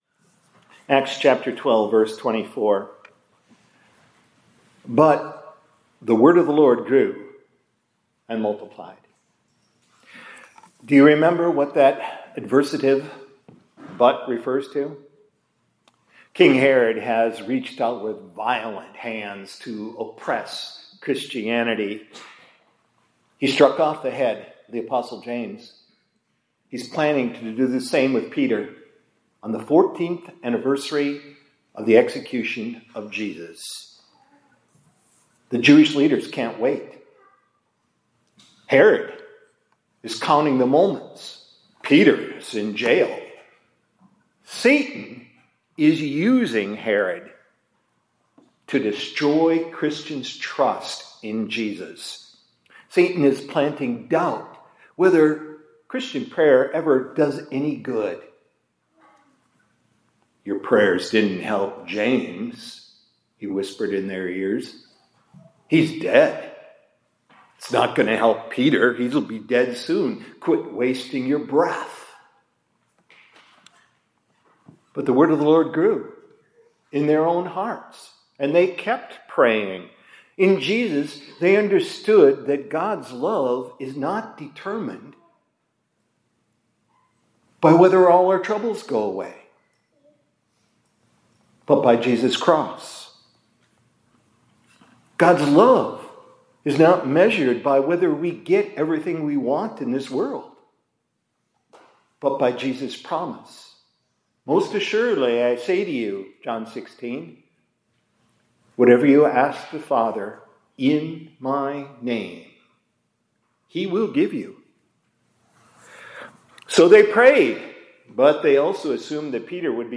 2025-11-18 ILC Chapel — One Little Word Can Fell…